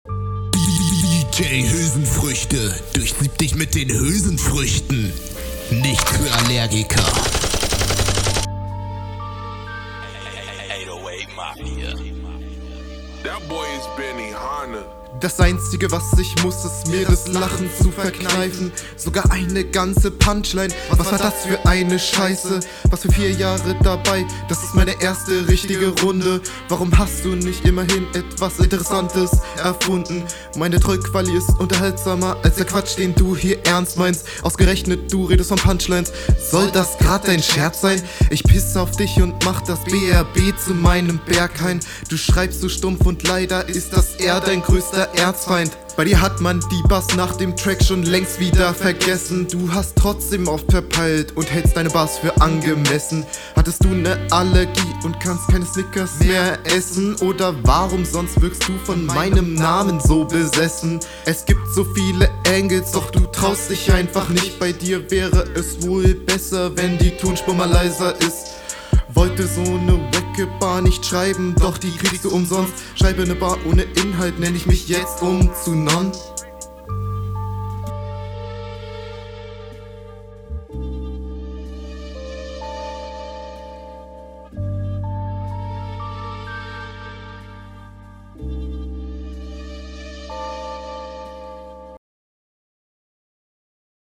Doubles nerven..